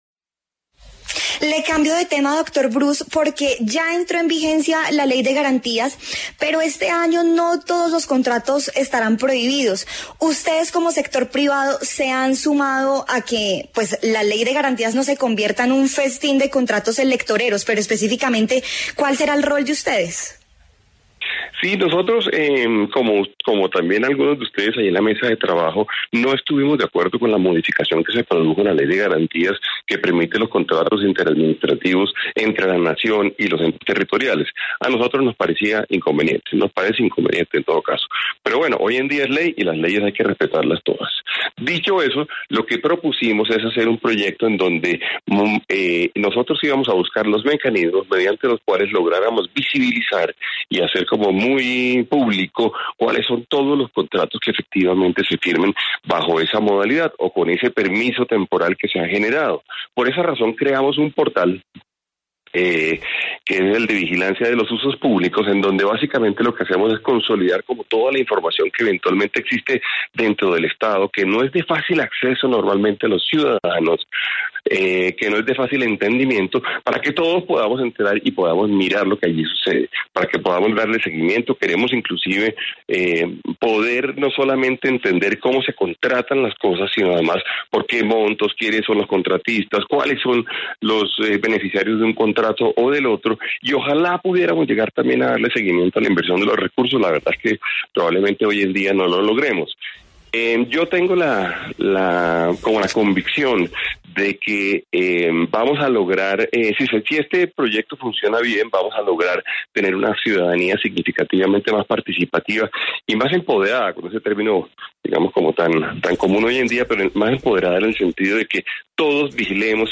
En La W, Bruce Mac Master, presidente de la Asociación Nacional de Empresarios, explicó cómo funcionará la plataforma.